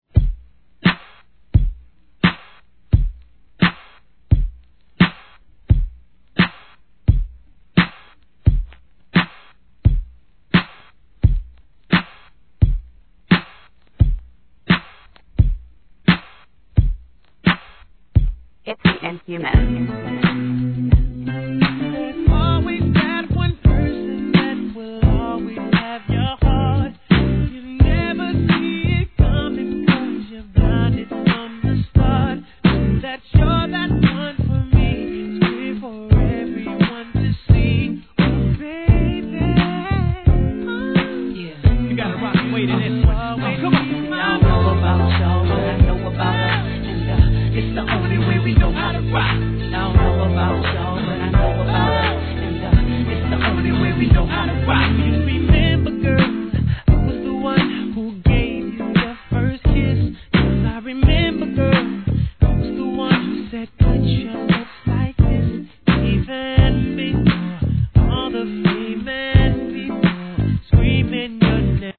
HIP HOP/R&B
REMIX、ブレンドのMUSH UPシリーズ第二弾!!